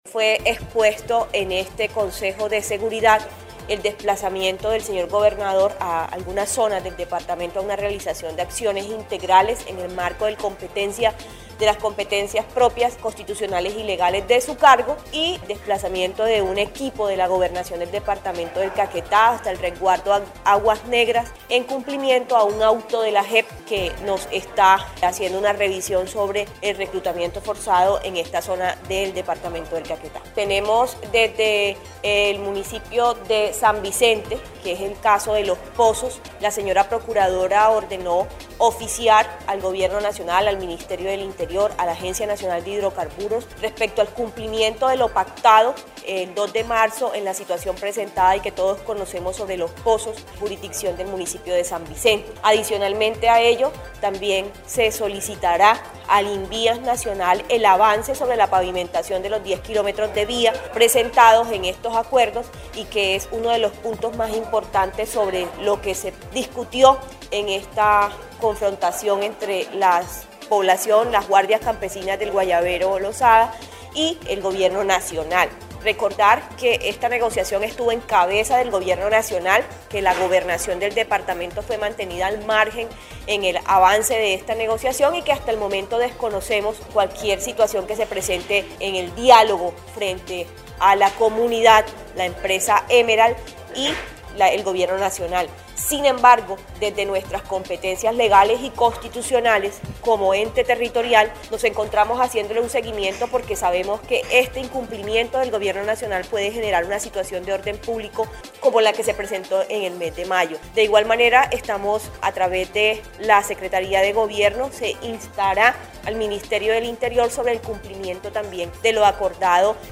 La secretaria de gobierno departamental, Sandra Rodríguez Pretelt, dijo que, se hizo la solicitud de la Procuradora Regional del Caquetá, Úrsula del Pilar Isaza Rivera, en el caso de Los Pozos, en el municipio de San Vicente del Caguán, para oficiar al gobierno nacional, al Ministerio del Interior y a la Agencia Nacional de Hidrocarburos, respecto al cumplimiento de lo pactado el pasado 2 de marzo de 2023.